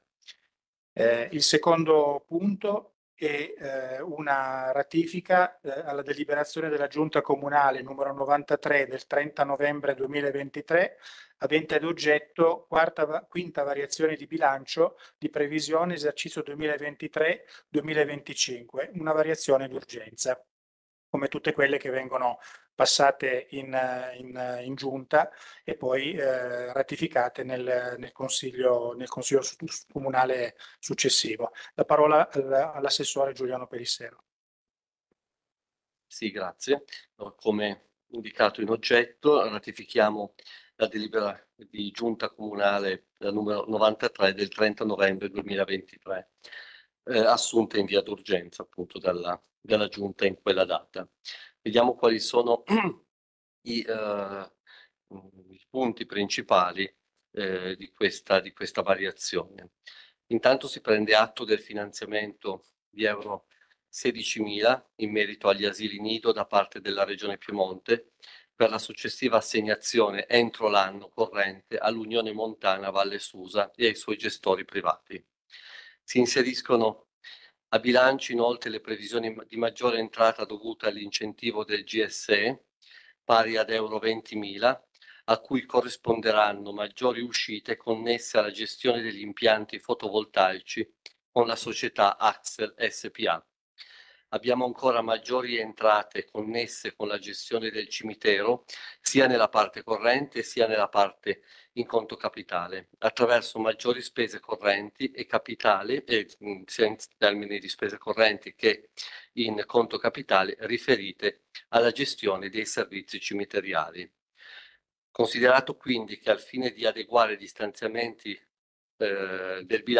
Consiglio Comunale Comune Susa 19_12_2023_Punto 2